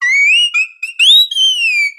Cri de Plumeline Style Hula dans Pokémon Soleil et Lune.
Cri_0741_Hula_SL.ogg